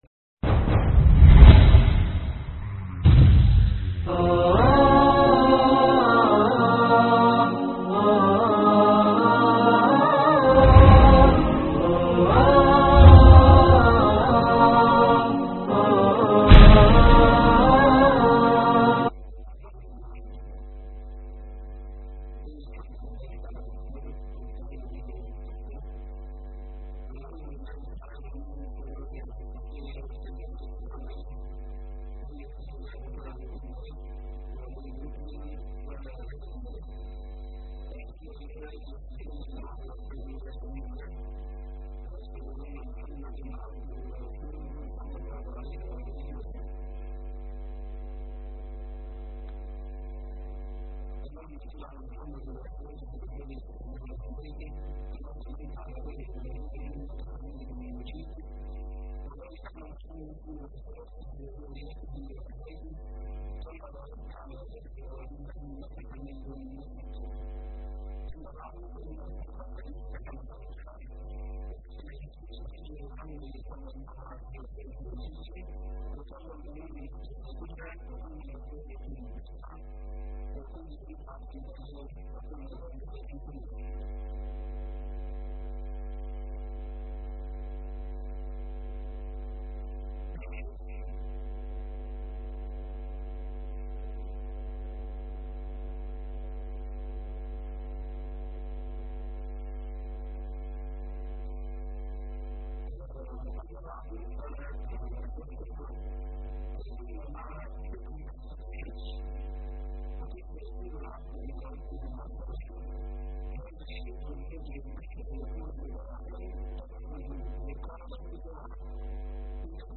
نظرات على واقعنا (محاضرات مسجد الحق)